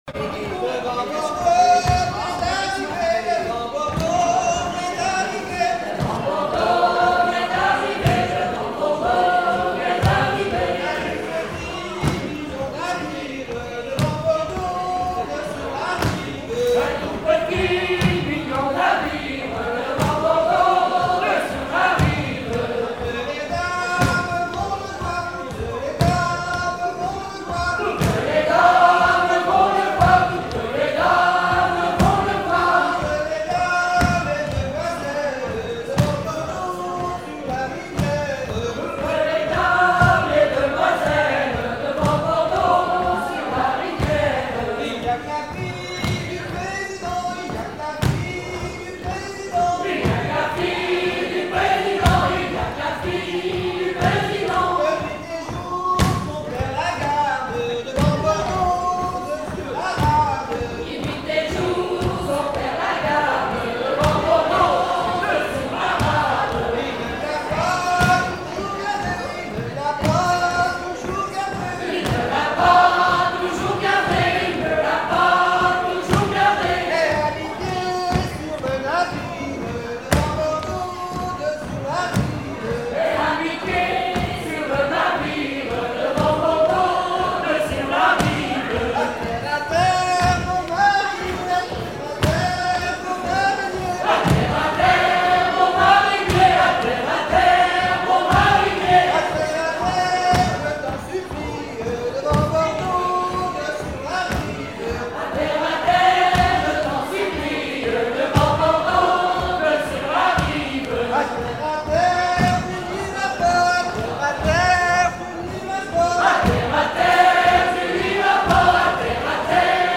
danse : ronde : demi-rond
Genre laisse
Présentation lors de la sortie de la cassette audio
Pièce musicale inédite